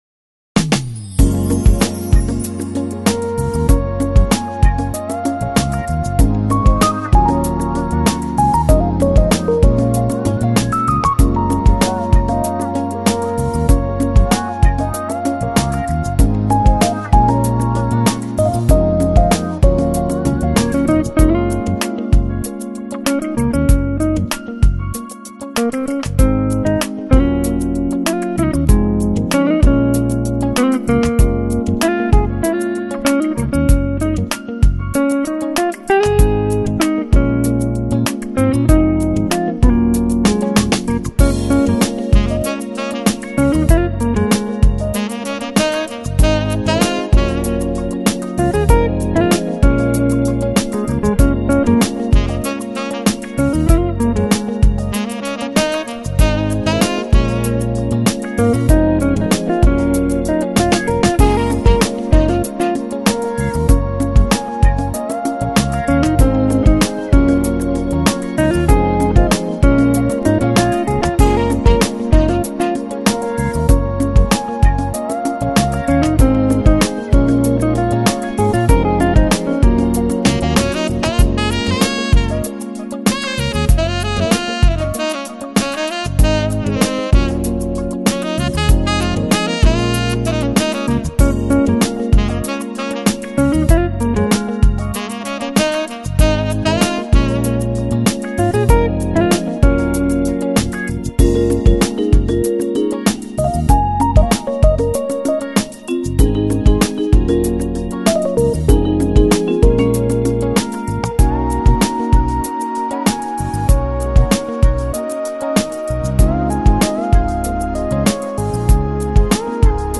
Lounge, Chill Out, Smooth Jazz, Easy Listening Год издания